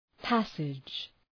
Προφορά
{‘pæsıdʒ}